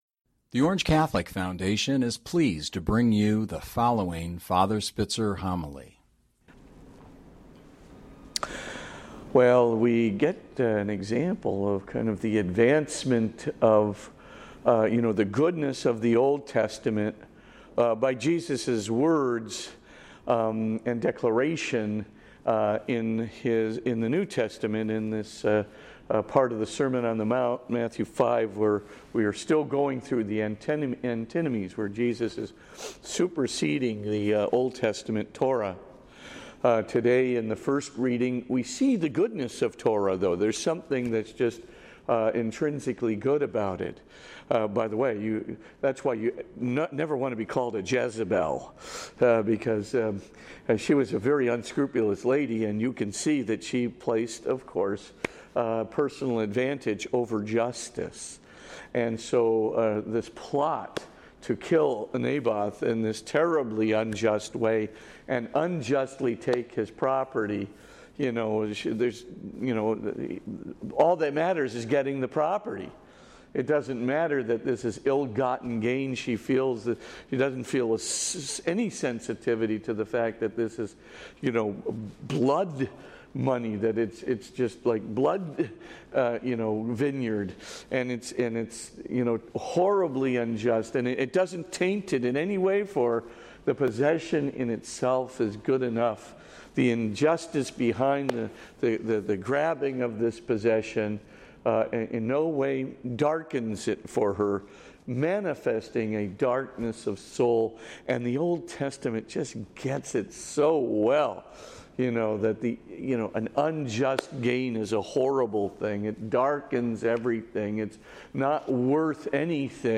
Podcast (fr_spitzer_homilies): Play in new window | Download